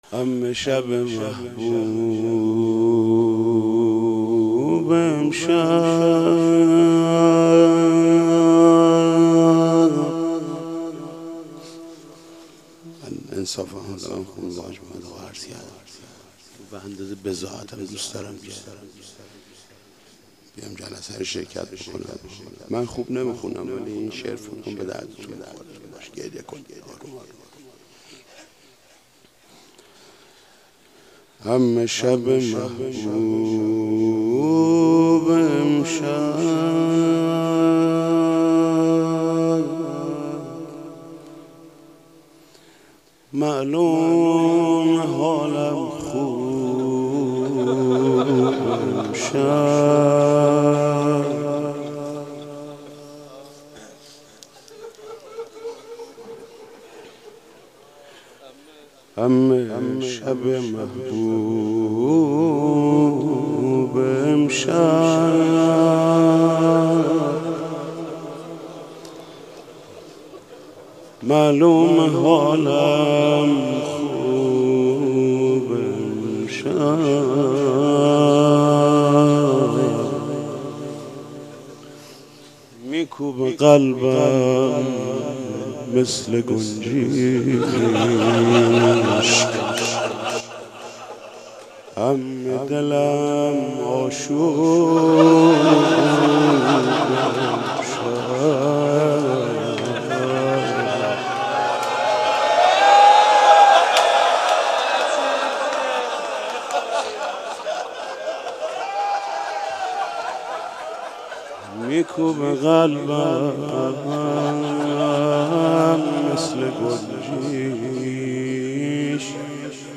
مناسبت : دهه دوم محرم
مداح : محمود کریمی قالب : روضه